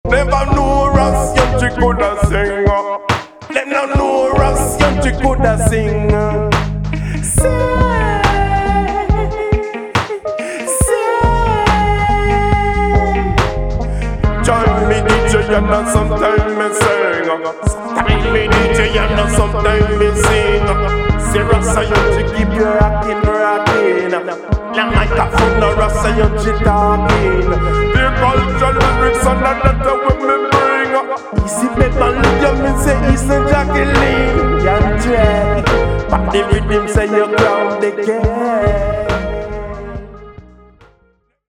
伝統的な要素と先鋭的な要素がブレンドされたレゲエ作品に仕上がっています。